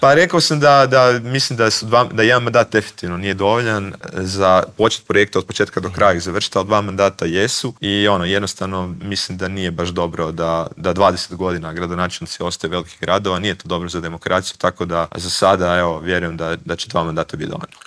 On je u prvom krugu lokalnih izbora osvojio 47,59 posto glasova Zagrepčana, a u Intervjuu tjedna Media servisa istaknuo je da je najvažnija većina u zagrebačkoj Gradskoj skupštini: